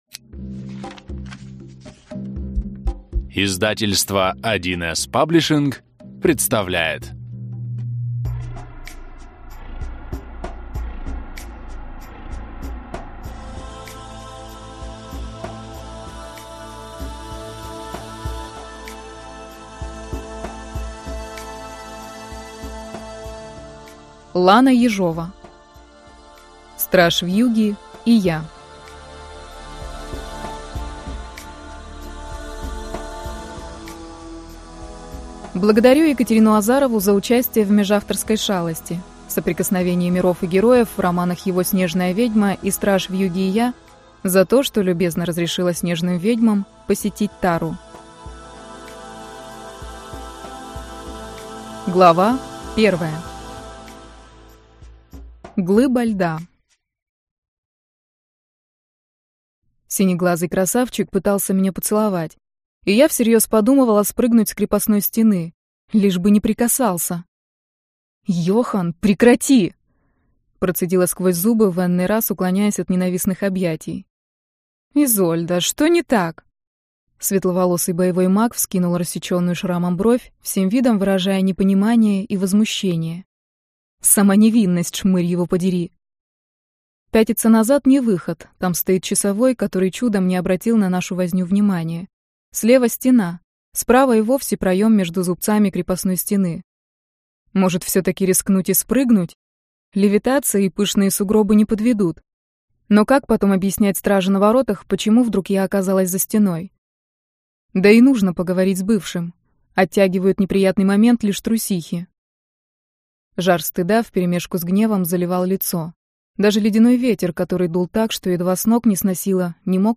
Аудиокнига Страж Вьюги и я - купить, скачать и слушать онлайн | КнигоПоиск